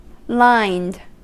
Ääntäminen
Ääntäminen US Haettu sana löytyi näillä lähdekielillä: englanti Käännös Adjektiivit 1. liniert Lined on sanan line partisiipin perfekti.